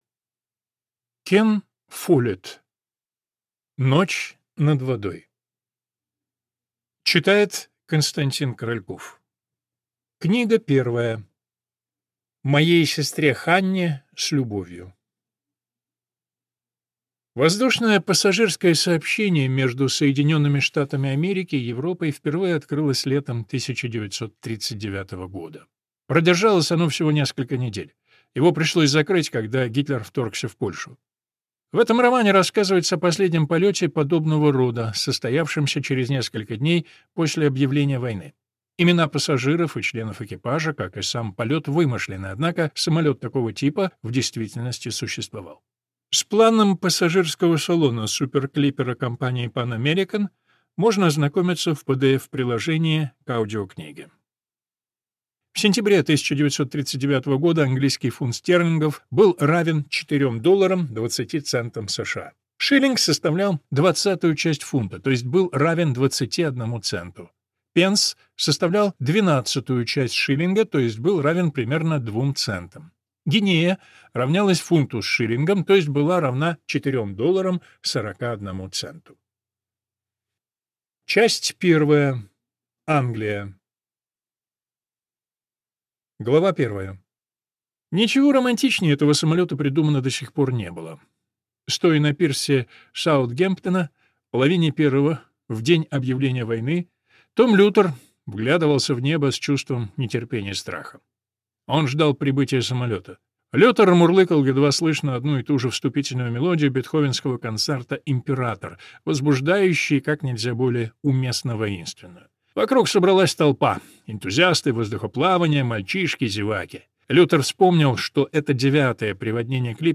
Аудиокнига Ночь над водой. Часть 1 | Библиотека аудиокниг